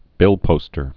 (bĭlpōstər)